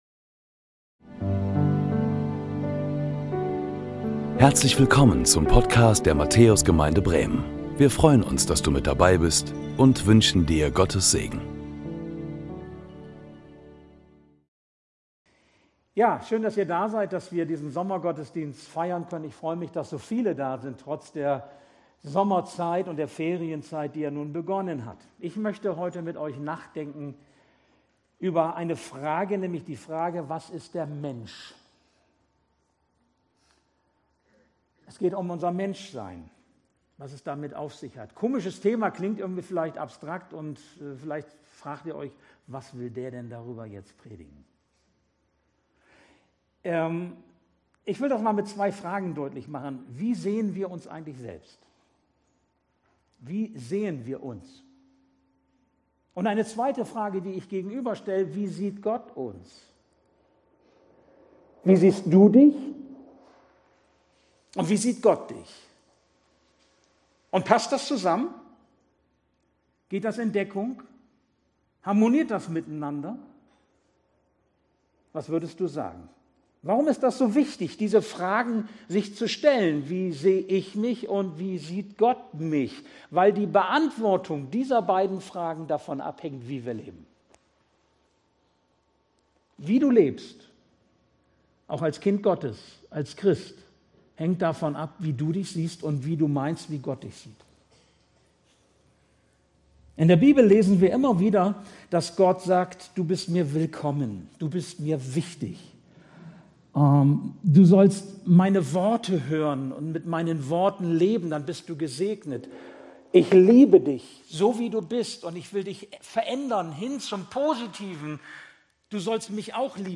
Predigten der Matthäus Gemeinde Bremen Was ist der Mensch ...?